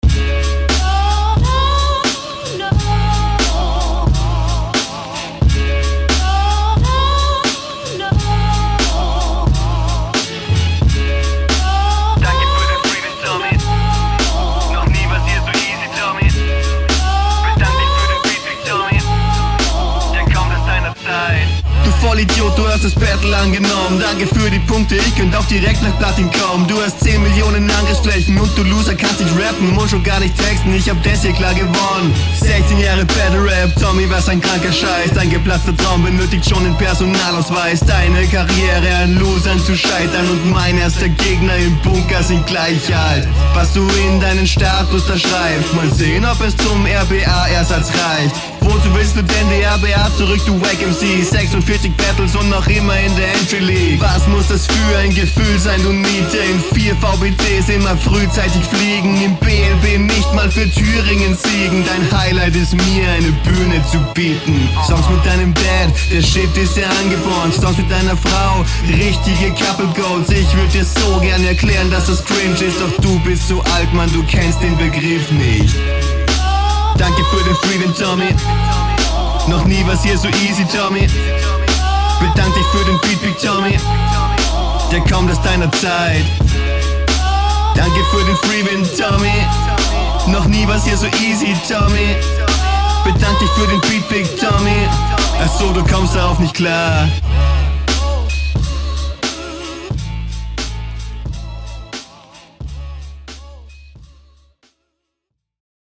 manchmal nen hauch vor dem takt, aber nur ganz minimal